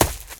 High Quality Footsteps
STEPS Leaves, Run 13.wav